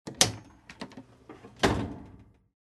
Звуки посудомоечной машины
Звук открывания отсека посудомойки